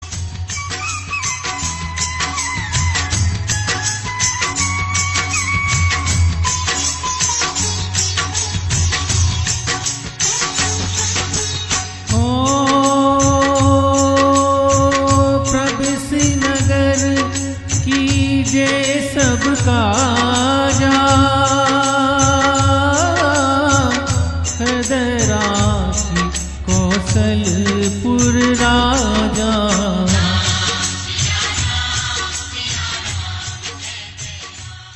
Bhajan Ringtones
Devotional Ringtones